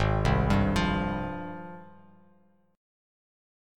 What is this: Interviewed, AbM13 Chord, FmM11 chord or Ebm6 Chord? AbM13 Chord